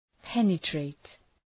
{‘penə,treıt}
penetrate.mp3